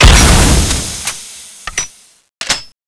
cannonex_ice_d_reload1.wav